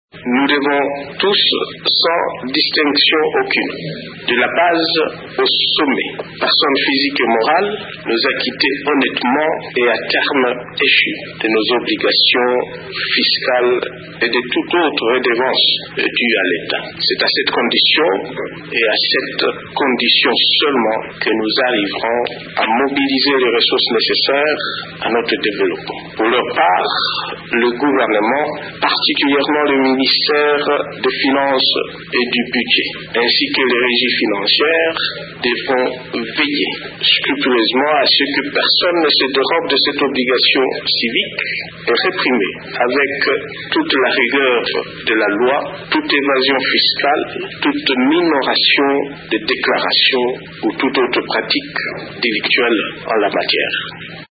Le président de la République s’est félicité dimanche dernier dans une adresse à la nation,  de l’atteinte du point d’achèvement. Cependant, Joseph Kabila a estimé que cette nouvelle ne devrait pas être comprise comme une licence pour s’endetter à nouveau et de manière inconsidérée.
Voici un extrait de son discours: